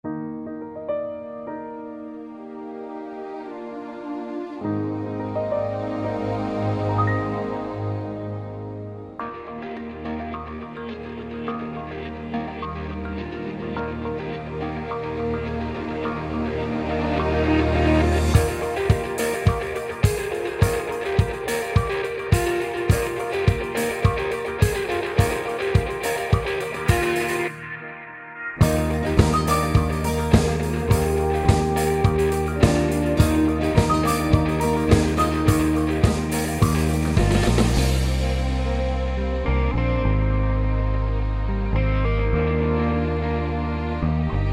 • Качество: 128, Stereo
без слов
красивая мелодия
инструментальные
вдохновляющие
легкий рок
воодушевляющие
Неизменным остается одно - приятное звучание шести нот.